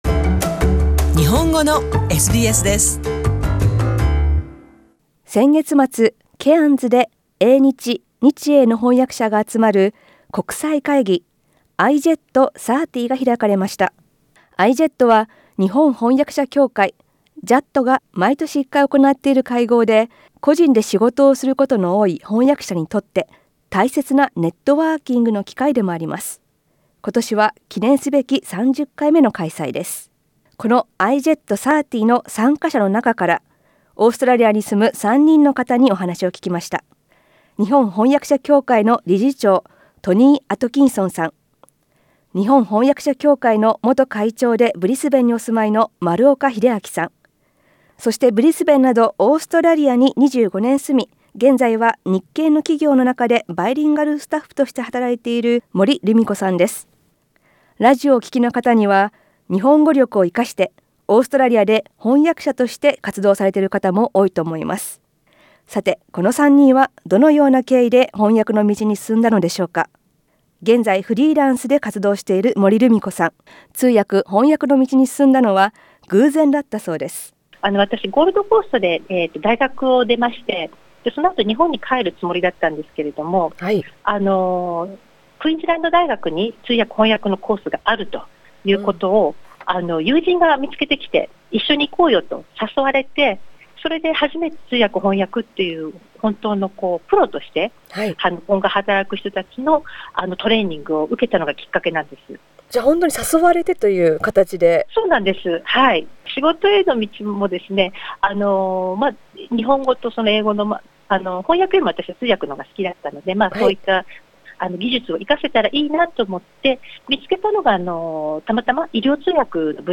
音声では、IJET-30に参加した人の中から、オーストラリアに住む3人の翻訳家にお話を聞きました。